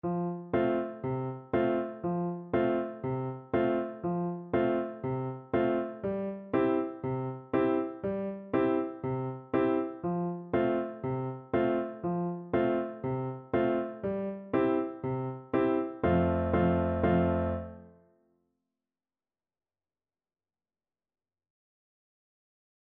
Play (or use space bar on your keyboard) Pause Music Playalong - Piano Accompaniment Playalong Band Accompaniment not yet available reset tempo print settings full screen
F major (Sounding Pitch) (View more F major Music for Voice )
4/4 (View more 4/4 Music)
Allegro =c.120 (View more music marked Allegro)
Traditional (View more Traditional Voice Music)